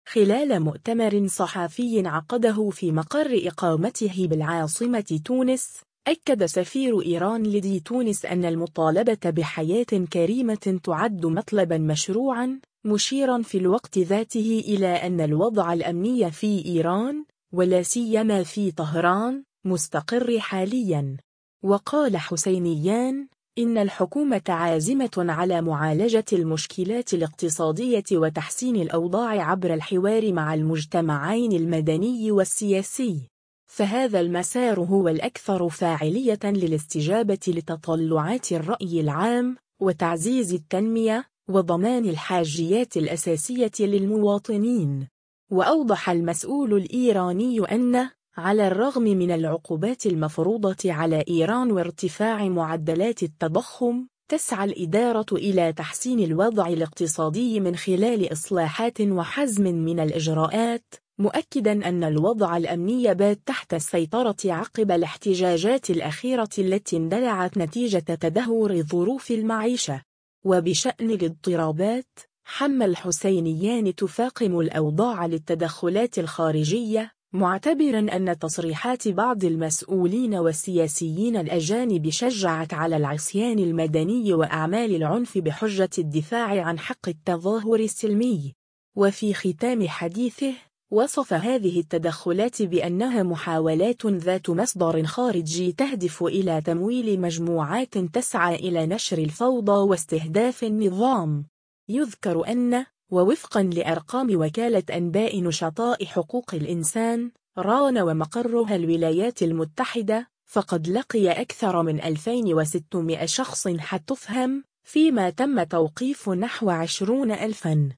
خلال مؤتمر صحافي عقده في مقر إقامته بالعاصمة تونس، أكد سفير إيران لدى تونس أن المطالبة بـ«حياة كريمة» تُعد مطلبًا مشروعًا، مشيرًا في الوقت ذاته إلى أن الوضع الأمني في إيران، ولا سيما في طهران، مستقر حاليًا.